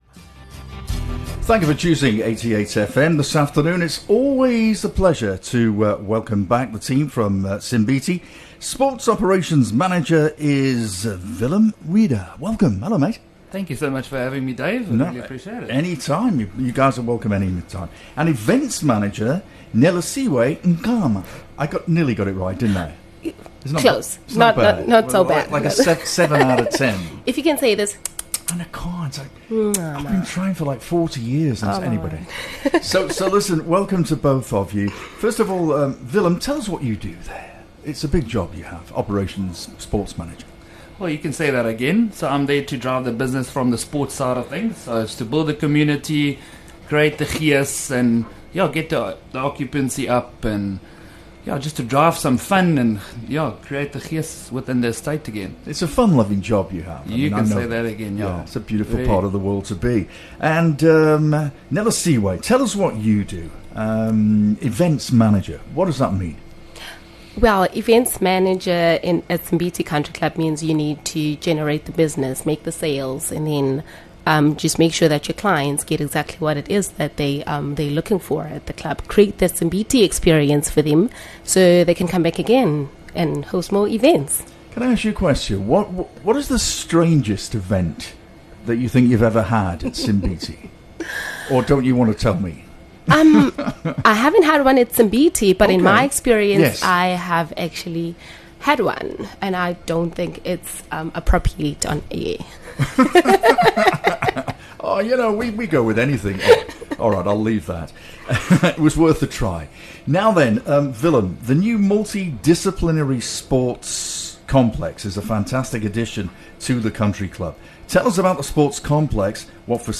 The interview will spotlight sports and events at Simbithi